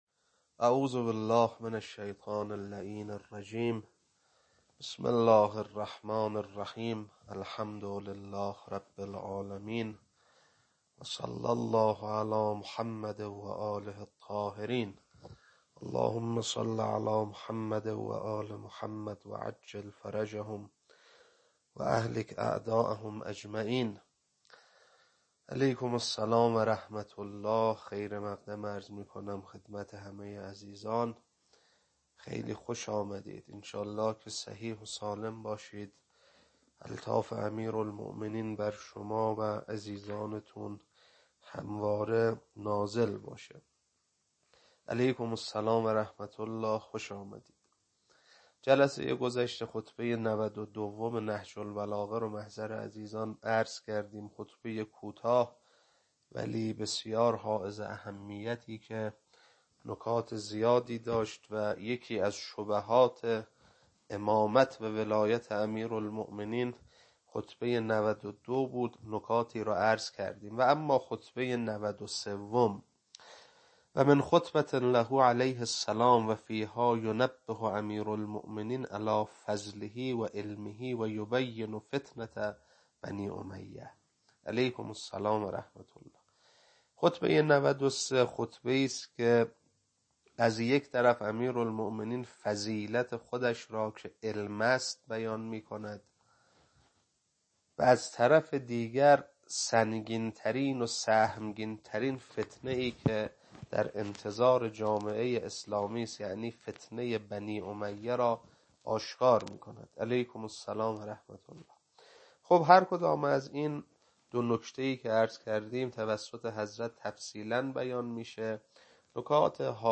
خطبه 93 بخش اول.mp3
خطبه-93-بخش-اول.mp3